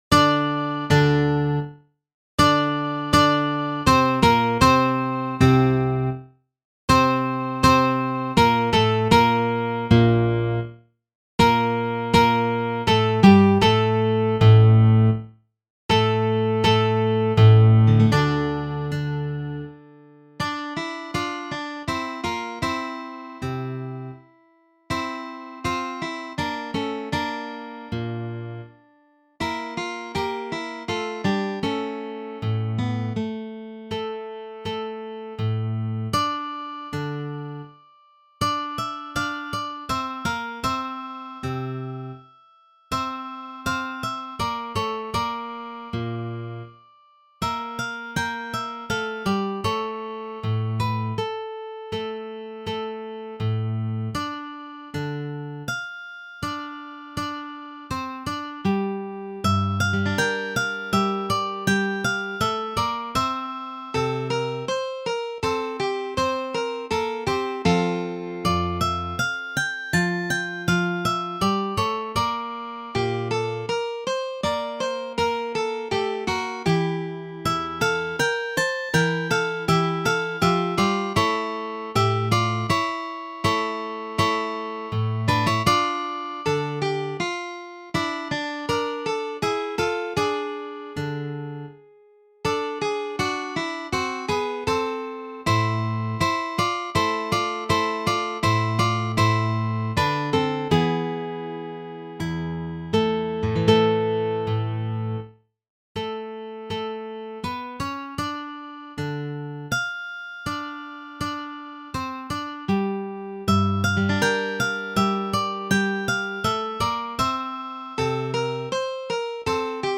Movt. 2–solo guitars